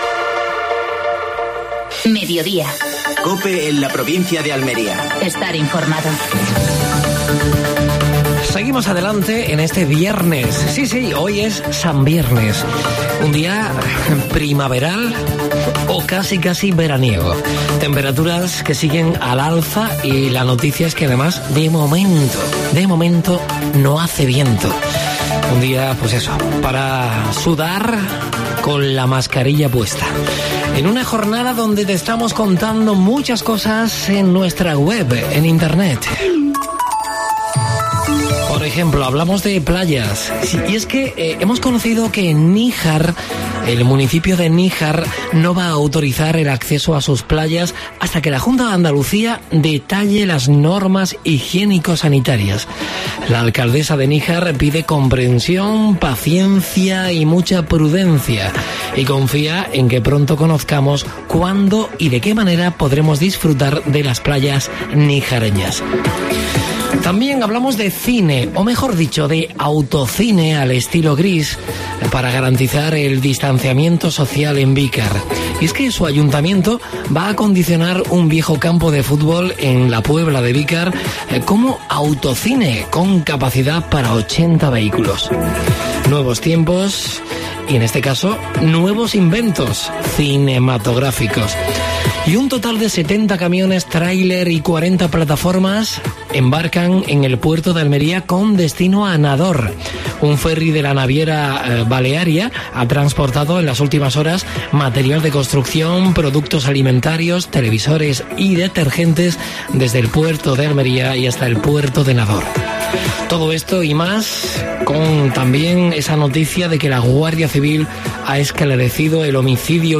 AUDIO: Entrevista al alcalde de Íllar, Carmelo Llobregat.